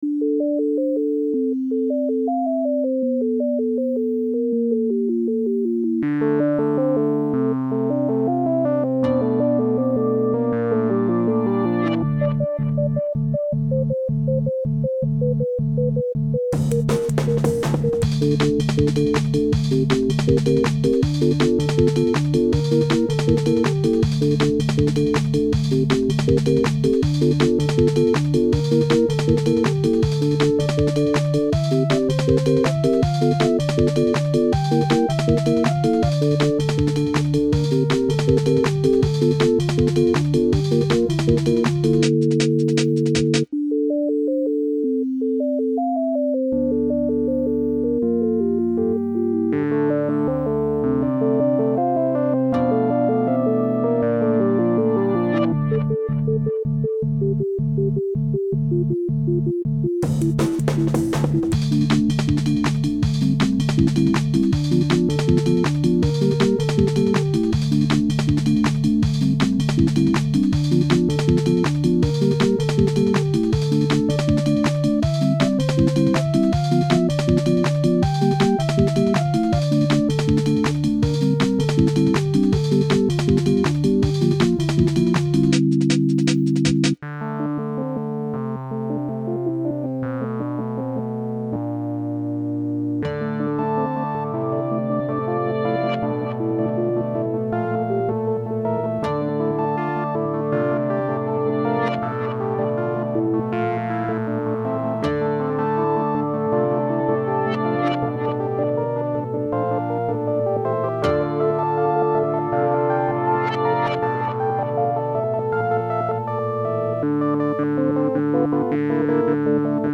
Key Instruments: Synth